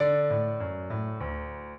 piano
minuet15-12.wav